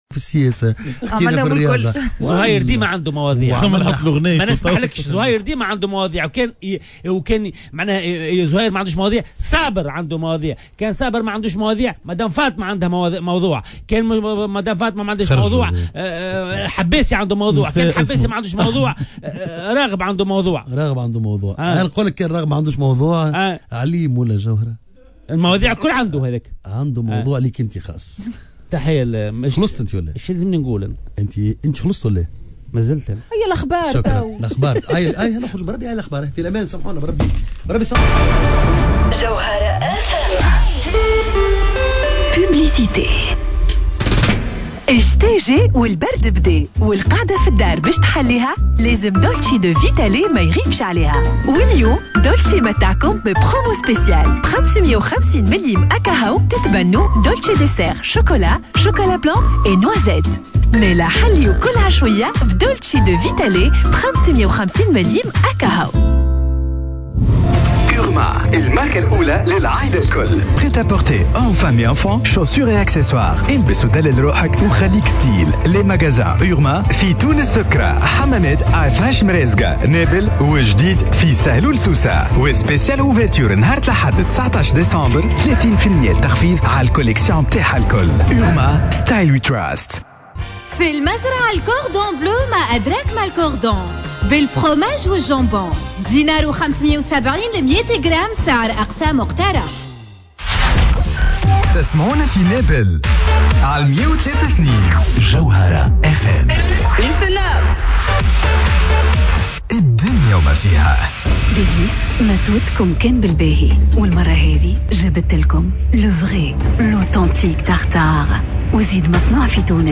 نشرة أخبار منتصف النهار ليوم الإربعاء 22 ديسمبر 2021